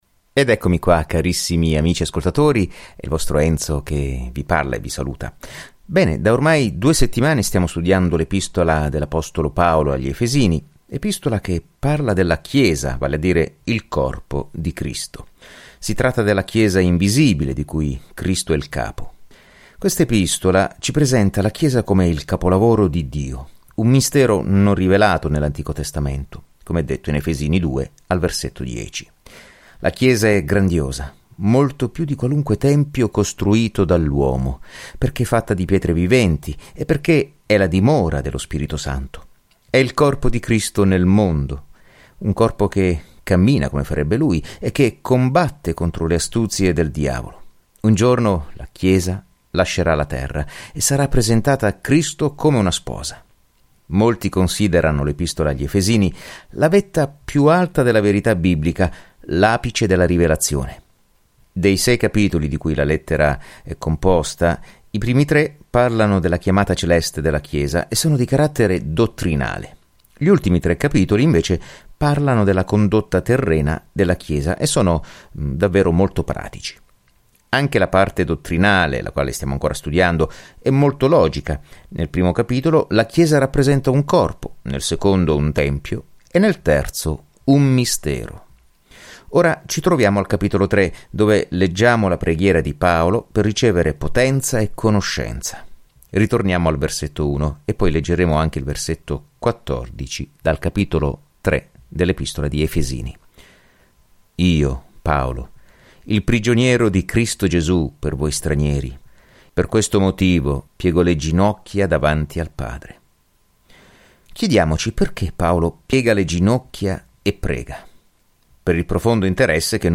Scrittura Lettera agli Efesini 3:1-21 Lettera agli Efesini 4:1 Giorno 10 Inizia questo Piano Giorno 12 Riguardo questo Piano Dall’alto di ciò che Dio vuole per i suoi figli, la lettera agli Efesini spiega come camminare nella grazia, nella pace e nell’amore di Dio. Viaggia ogni giorno attraverso la Lettera agli Efesini mentre ascolti lo studio audio e leggi versetti selezionati della parola di Dio.